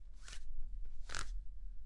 描述：卷帘的快门向上或向下移动
Tag: 幻灯片 窗口 快门